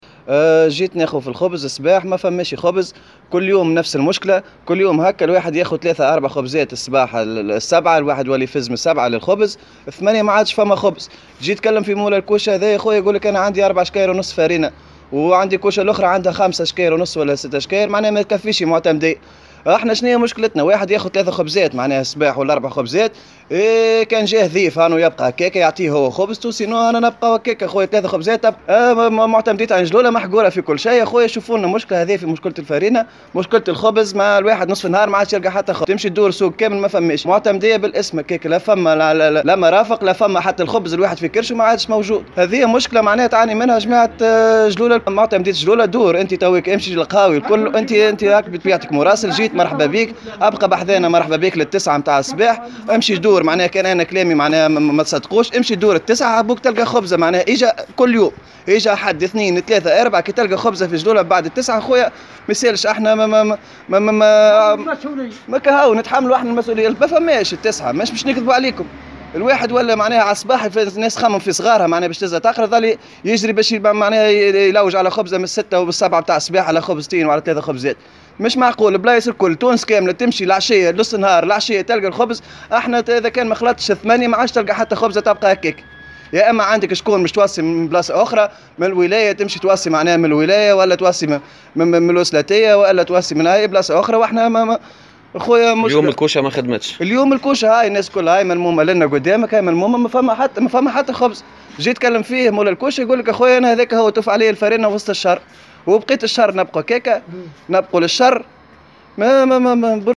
مواطن